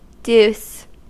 Ääntäminen
IPA : /djuːs/ IPA : /d͡ʒuːs/ US : IPA : /duːs/